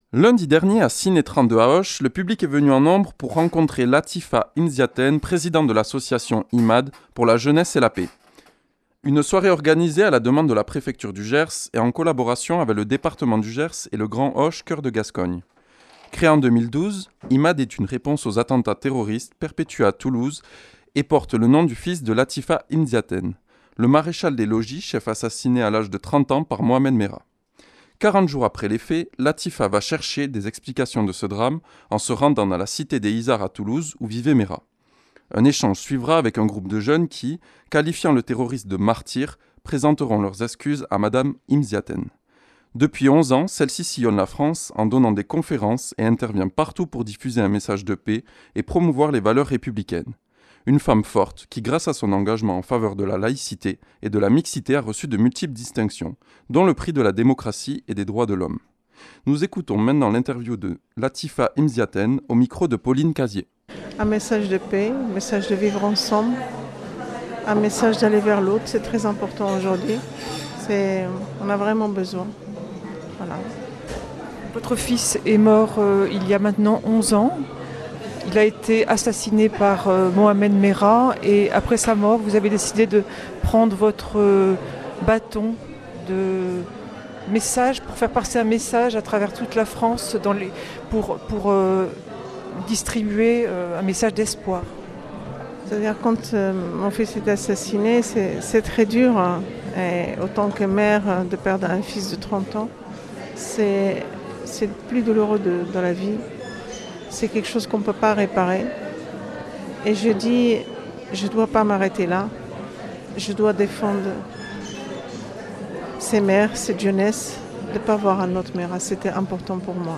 Interview et reportage du 29 nov.